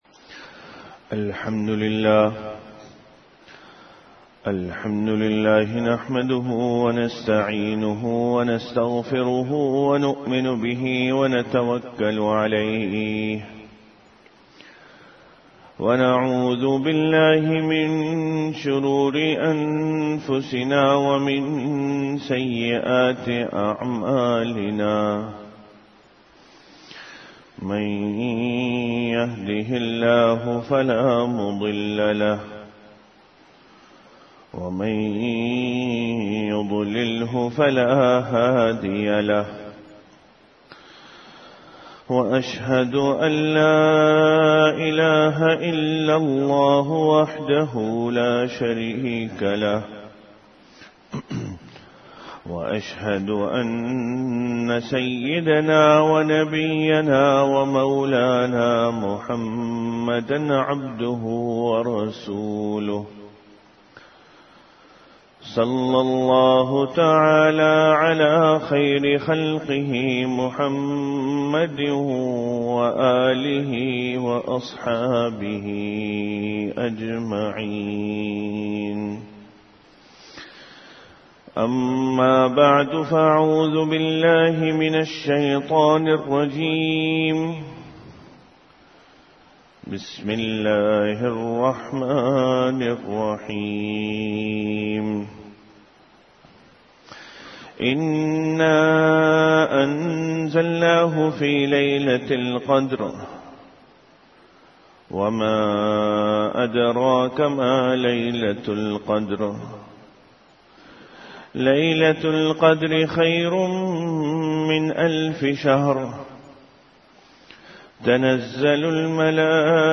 An Urdu Islamic audio lecture on Bayanat, delivered at Jamia Masjid Bait-ul-Mukkaram, Karachi.